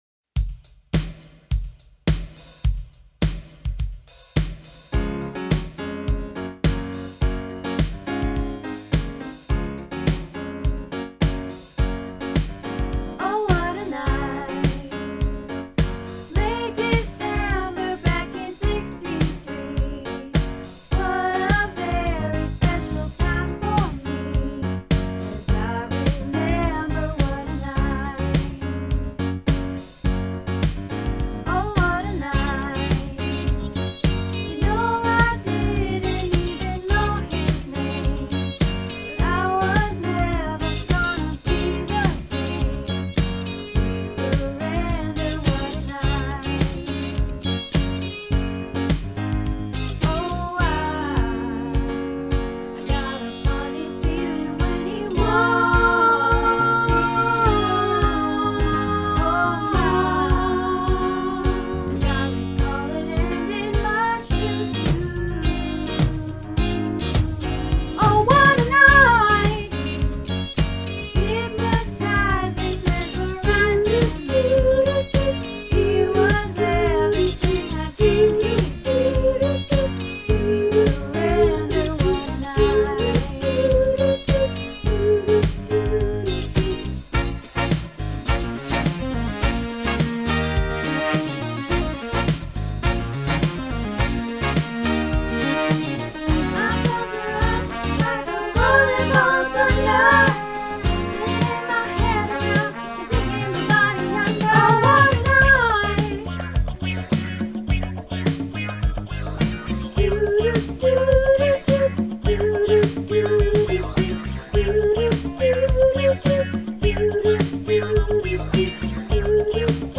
Oh What a Night - Soprano